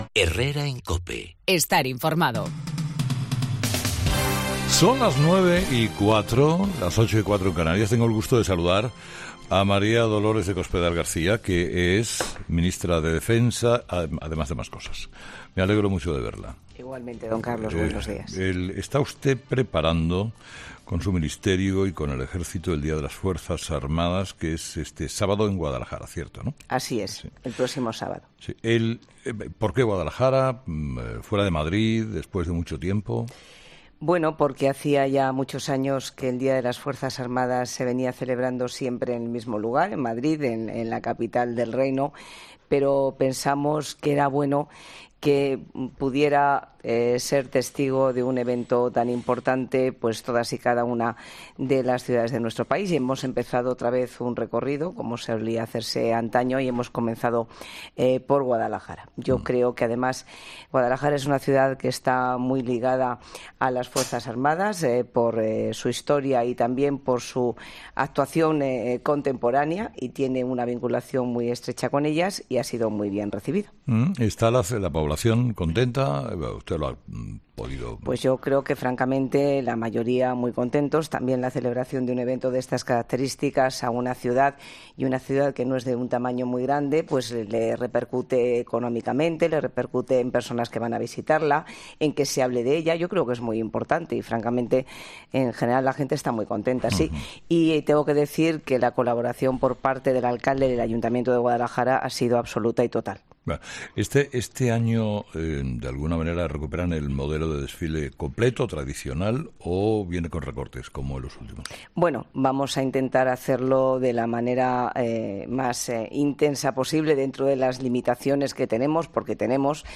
Escucha la entrevista a María Dolores de Cospedal en 'Herrera en COPE'